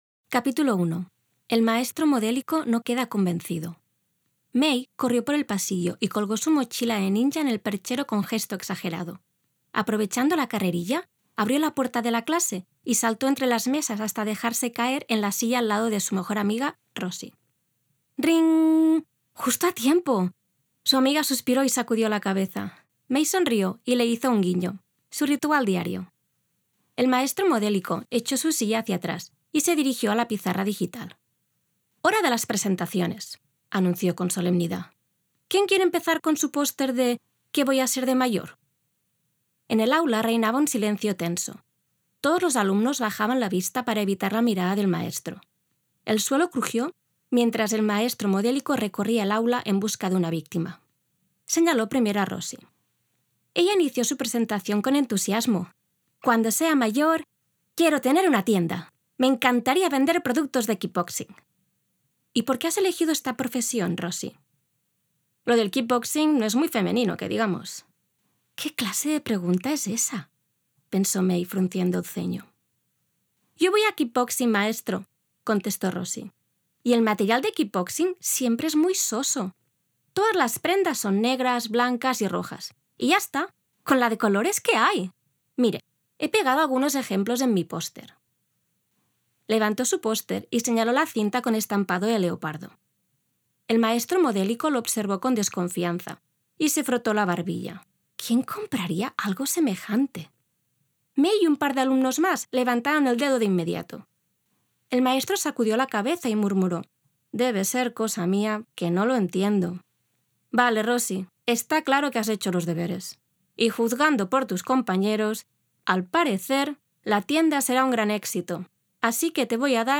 Audiobook – Storyteller/Voice Over